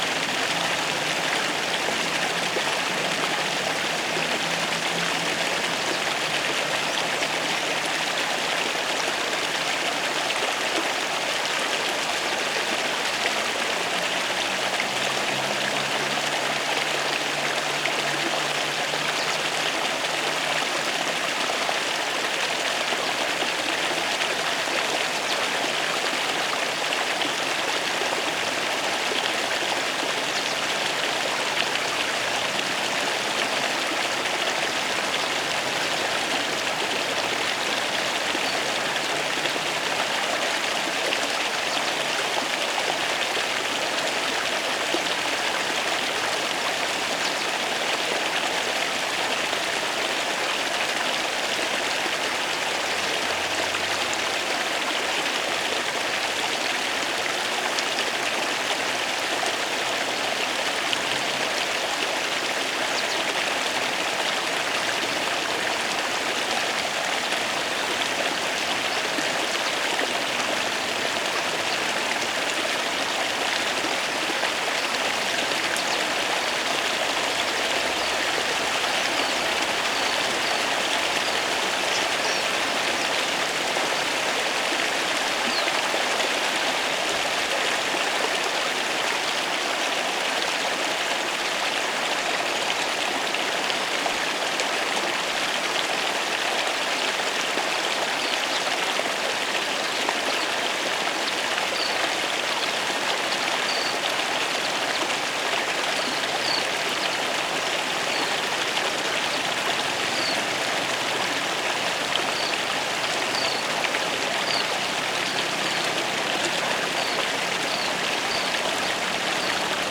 Recorrido sonoro por nuestro país
esa-jujuy-san-salvador-de-jujuy-rio-grande.mp3